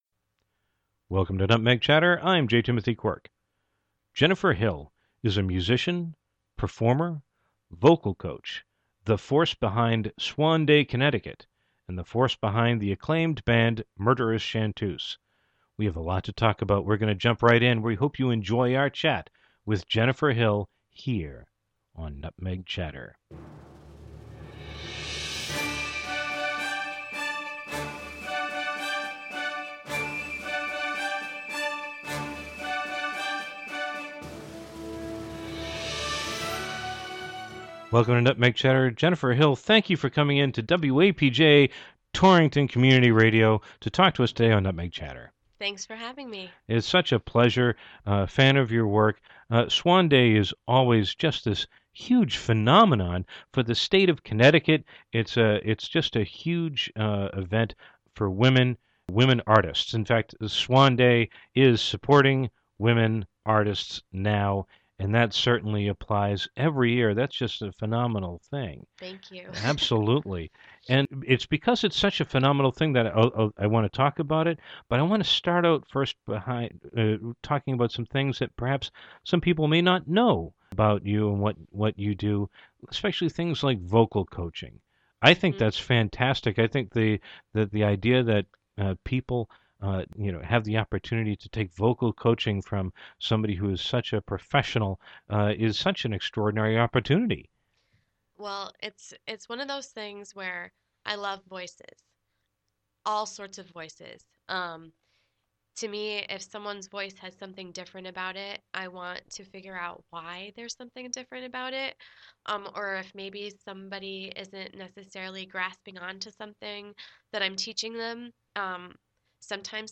Radio Show Tagged as